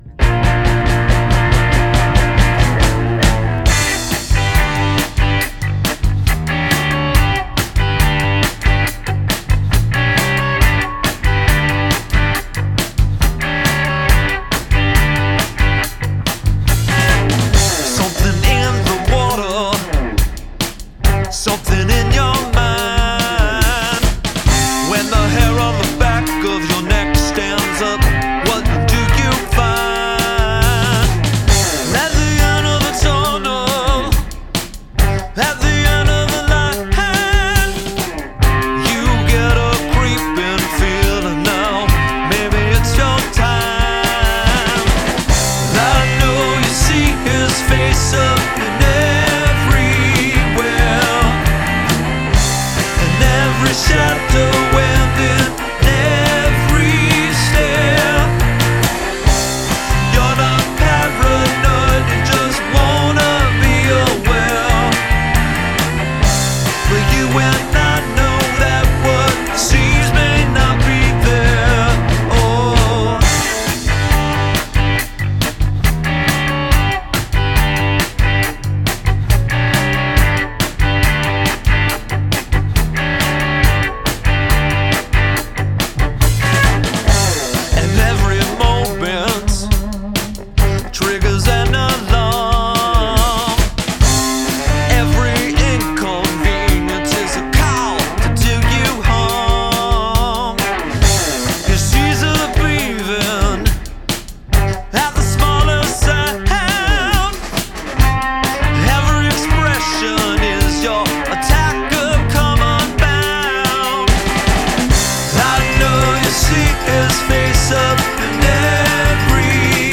Use of field recording
Oakland Amtrak: train sounds, ambience, percussion